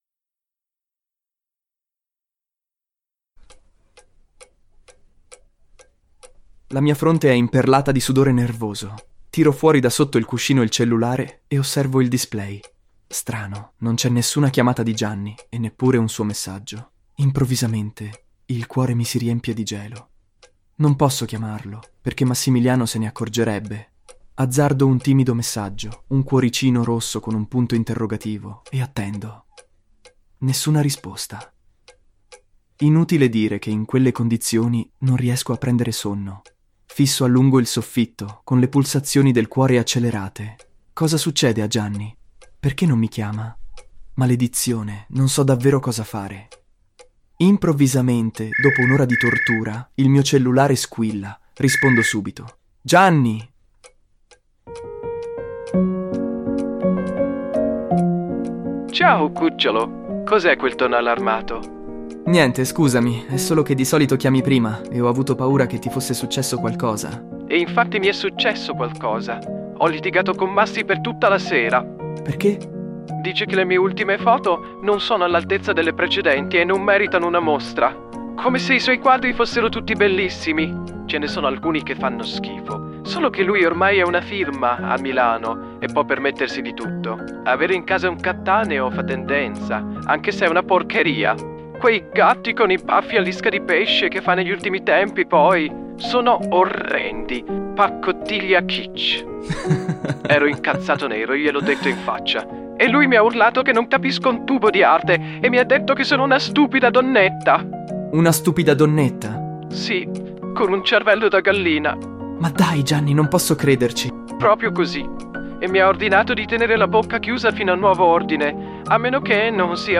La colonna sonora include "Valzer d'inverno" di Andrea Vanzo.